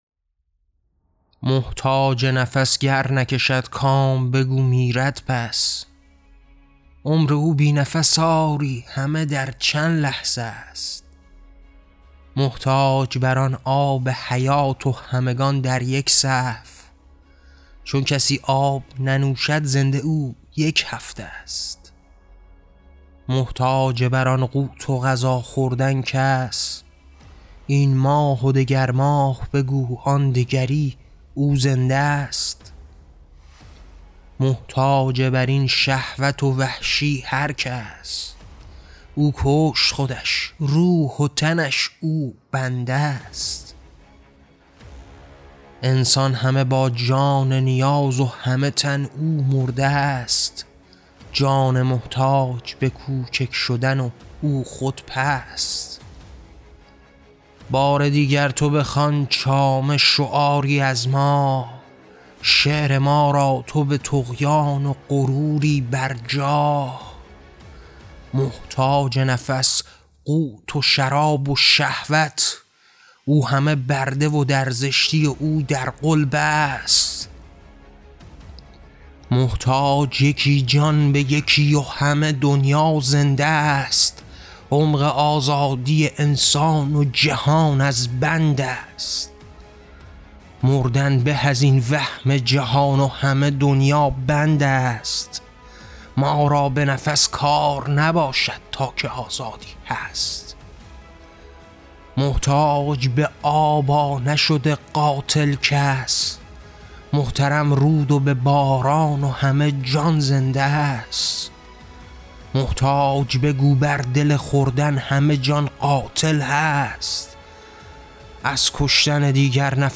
شعر صوتی محتاج